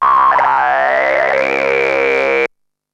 E T TALKS 2.wav